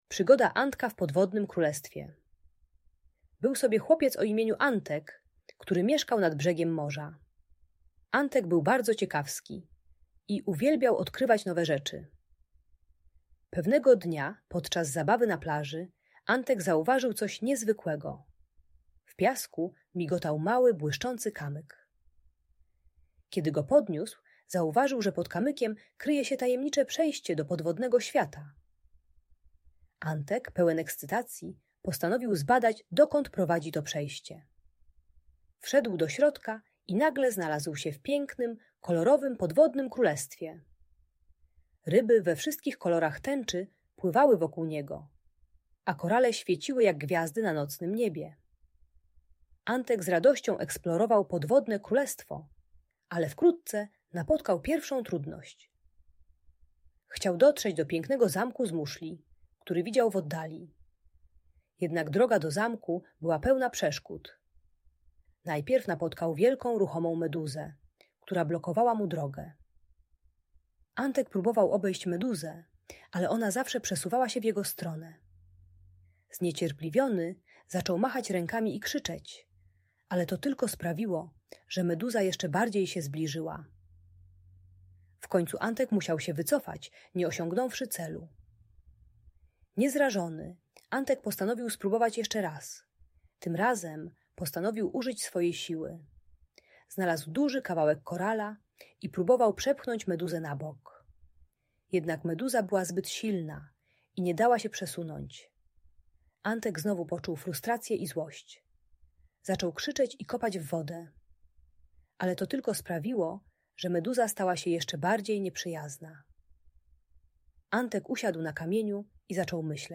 Przygoda Antka w Podwodnym Królestwie - Audiobajka